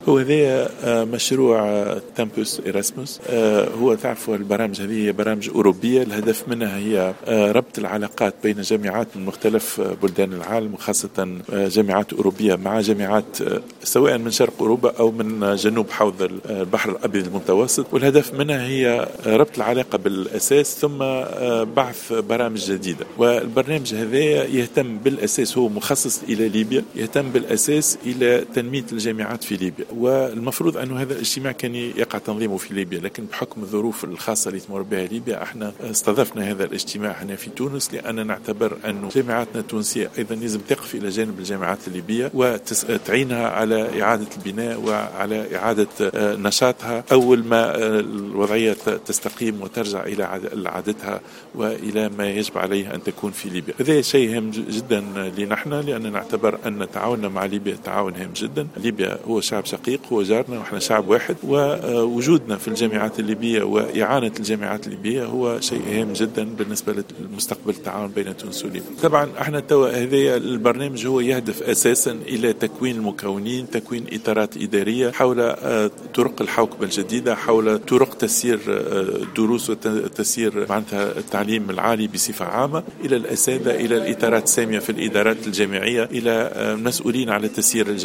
وقال في تصريحات لـ "الجوهرة أف أم" على هامش ملتقى أوروبي تونسي ليبي "ايرسميس" انعقد اليوم الاثنين في سوسة، إن برنامجا أوروبيا مخصصا للجامعات الليبية ينتظم في تونس بصفة استثنائية بسبب الظروف الخاصة في ليبيا، مضيفا أن دور الجامعة التونسية هو الوقوف إلى جانب الجامعة الليبية واعادة بنائها.